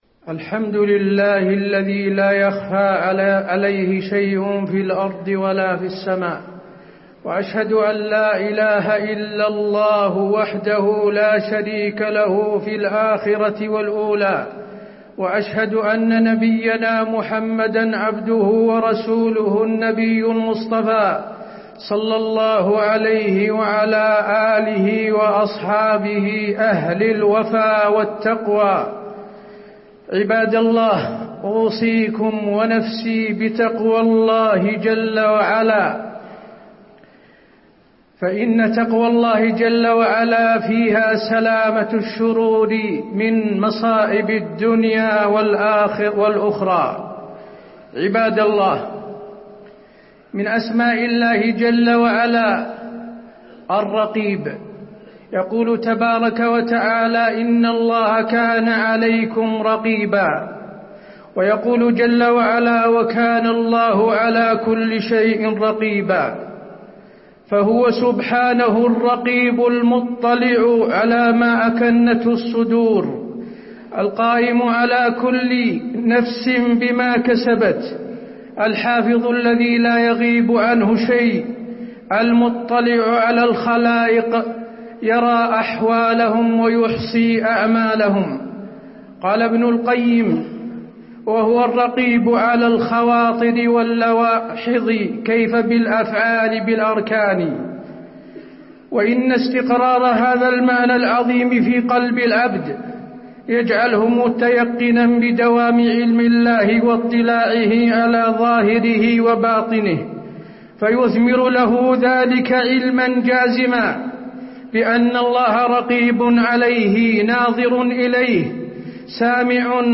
تاريخ النشر ٤ محرم ١٤٤٠ هـ المكان: المسجد النبوي الشيخ: فضيلة الشيخ د. حسين بن عبدالعزيز آل الشيخ فضيلة الشيخ د. حسين بن عبدالعزيز آل الشيخ مراقبة الله عزوجل